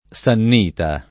sannita
sannita [ S ann & ta ]